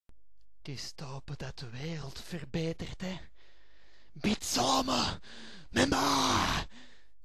De hongerige woorden kwam reutelend uit haar keel gekropen.